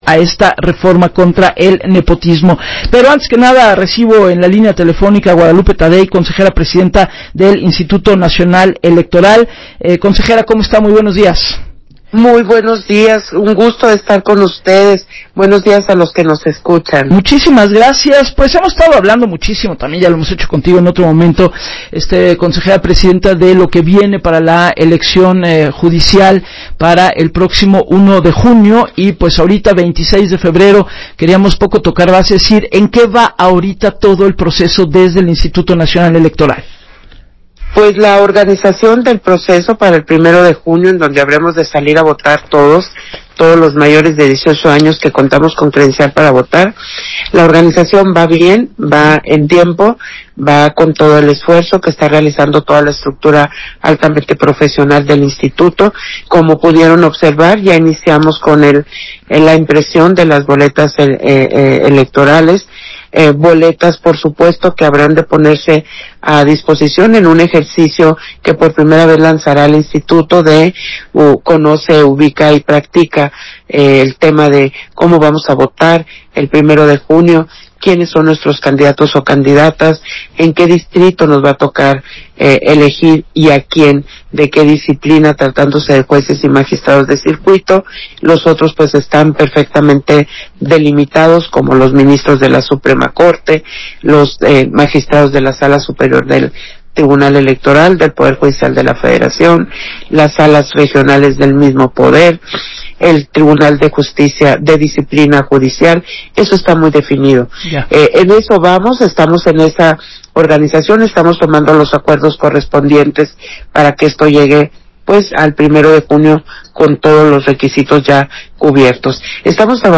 Entrevista de la Consejera Presidenta, Guadalupe Taddei con Gabriela Warkentin para W Radio